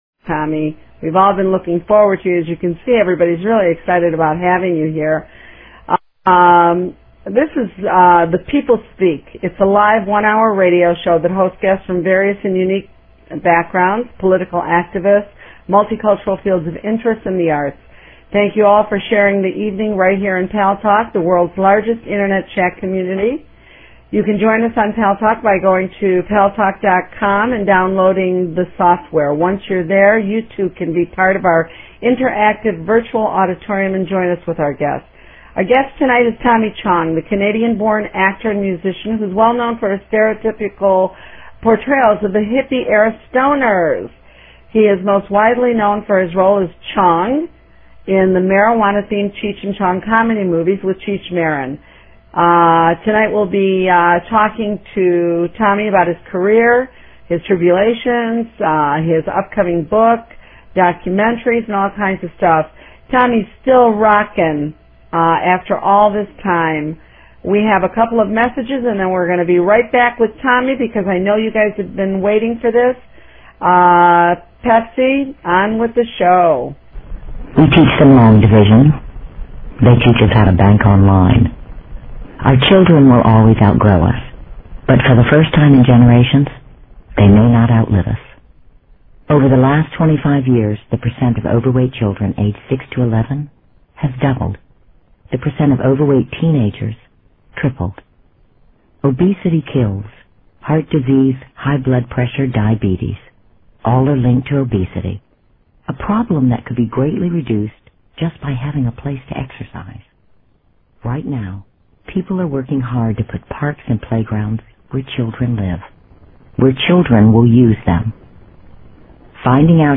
The People Speak with Guest, Tommy Chong